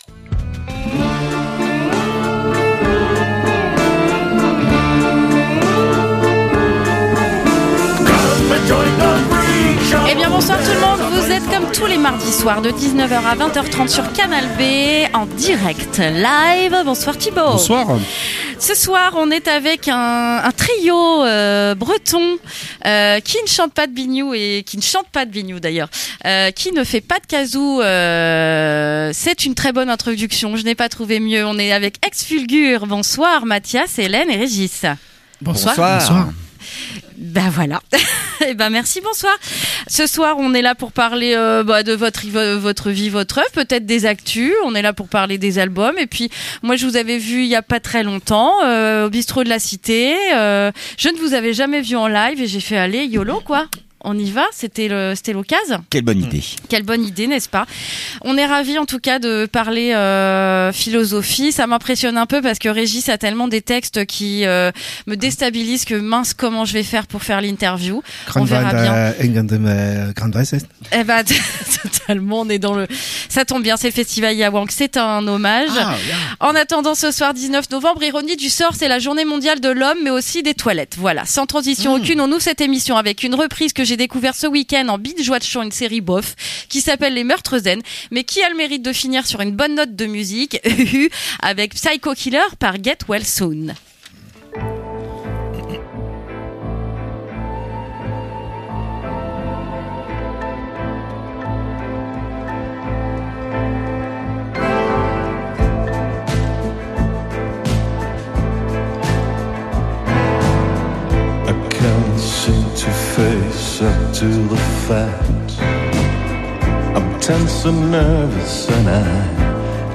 Le trio rennais Ex Fulgur (Kerviniou records) est venu en studio parler musique & poesie!